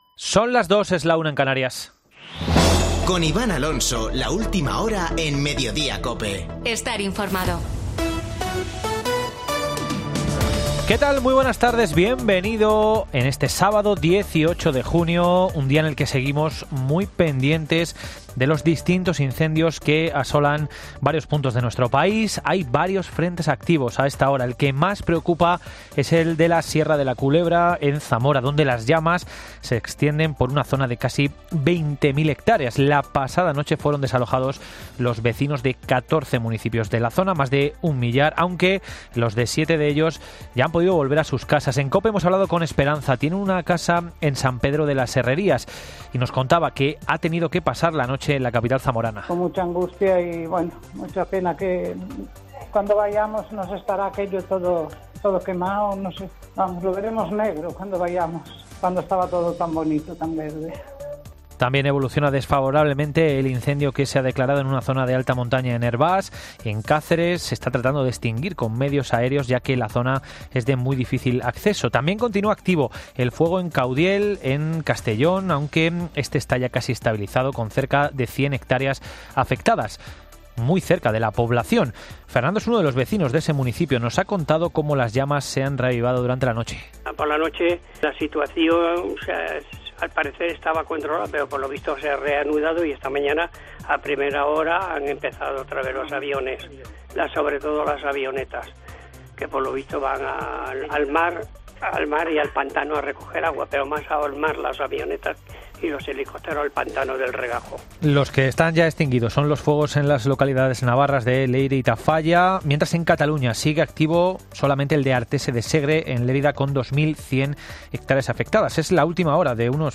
AUDIO: Boletín de noticias de COPE del 18 de junio de 2022 a la 14.00 horas